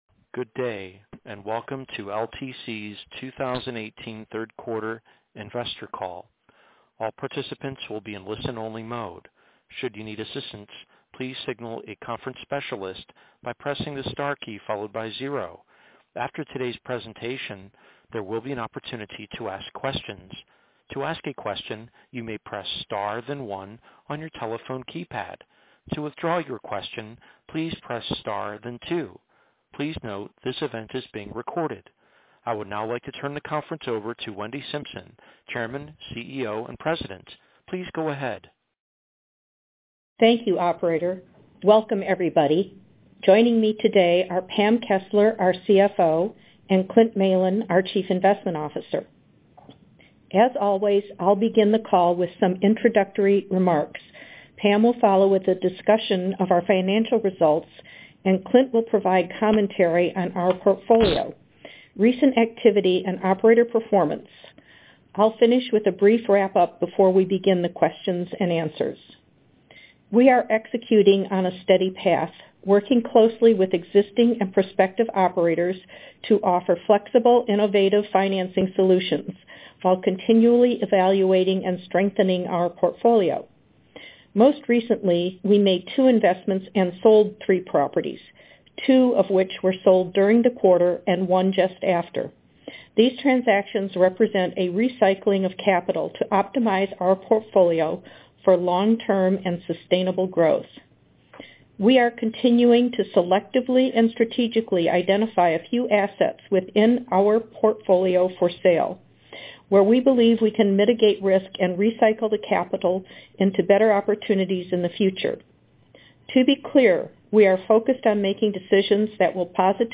Audio-Replay-of-LTC-Properties-Inc-Q3-2018-Earnings-Call.mp3